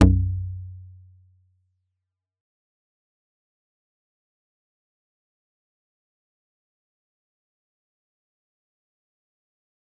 G_Kalimba-B1-f.wav